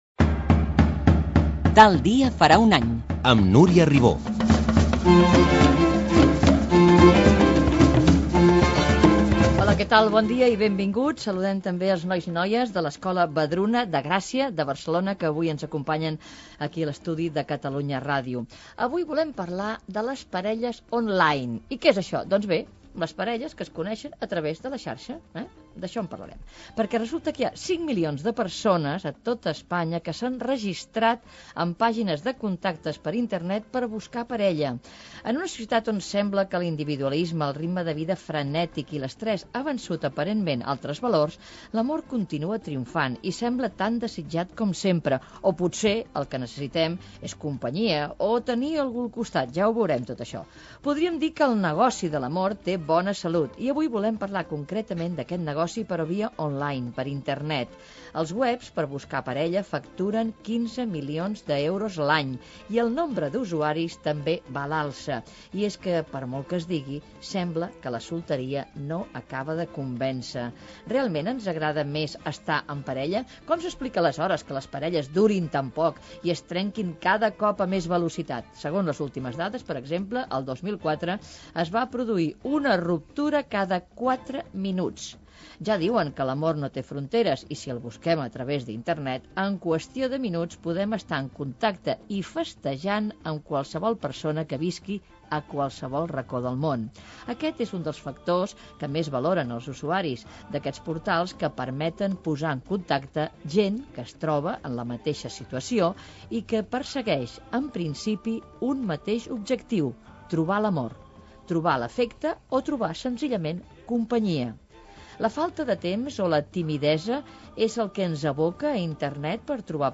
Careta del programa, presentació, contextualització del tema les parelles "on line" d'Internet.
Info-entreteniment